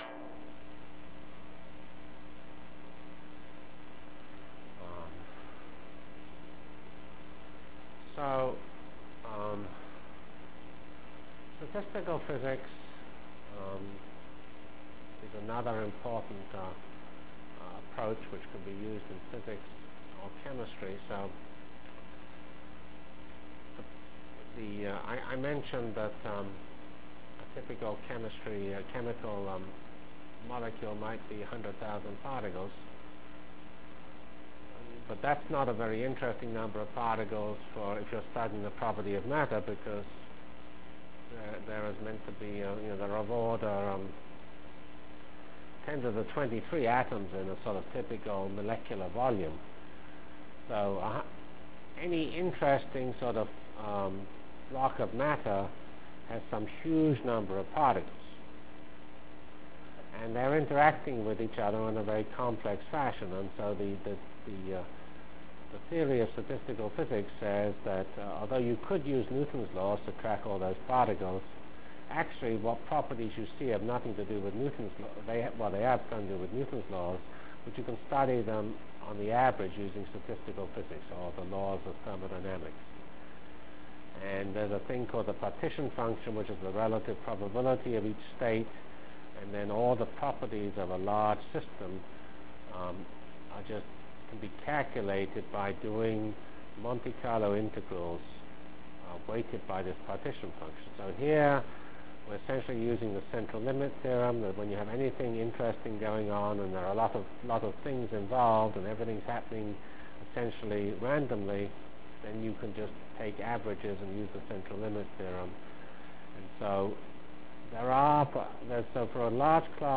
From CPS615-Physical Simulation Techniques and Structure of CFD Equations Delivered Lectures of CPS615 Basic Simulation Track for Computational Science -- 14 November 96.